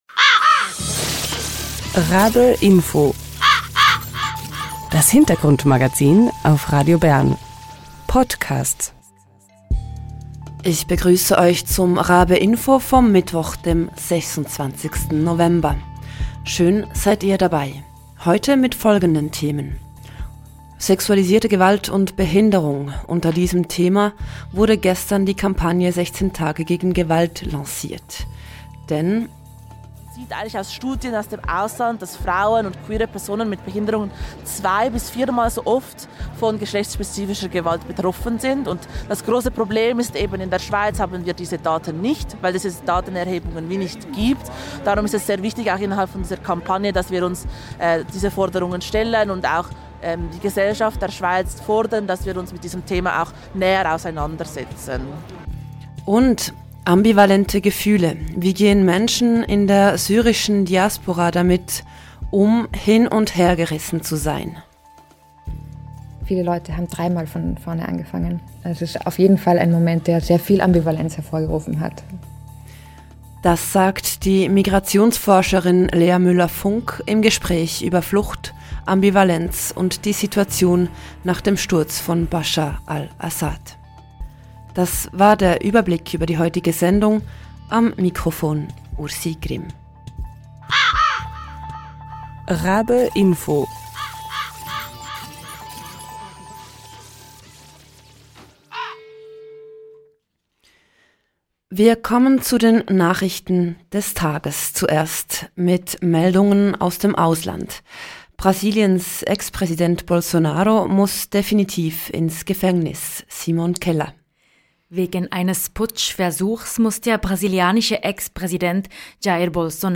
Im Talk hören wir von ihren Forschungserkenntnissen.